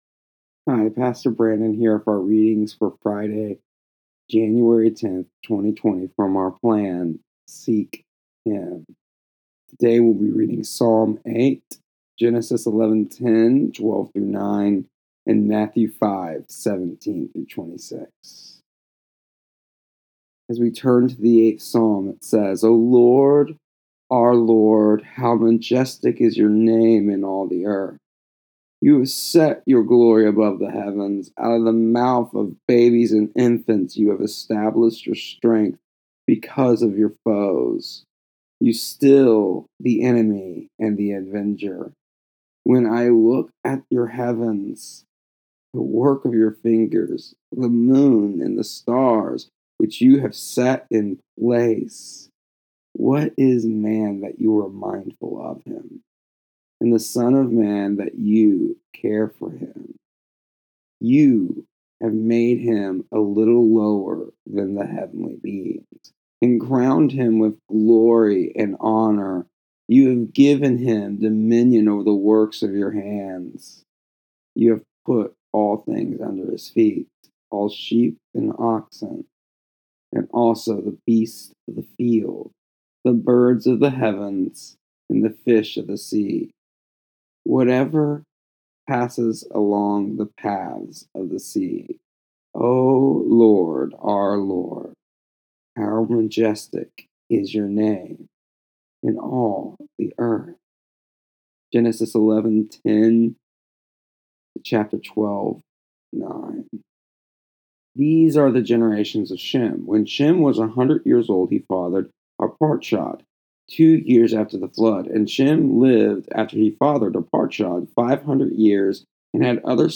Here is the audio version and daily devotional for, January 10th, 2020, of our daily reading plan.